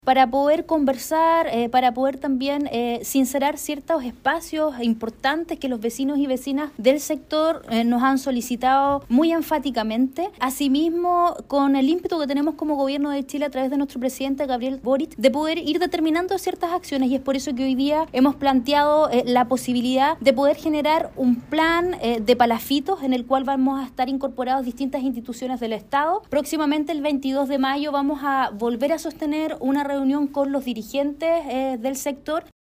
En tanto, la delegada presidencial regional de Los Lagos, Paulina Muñoz, que presentó el “Plan Palafitos”, indicó que en la instancia estarán incorporadas diferentes instituciones del Estado.
delegada-plan-palafitos.mp3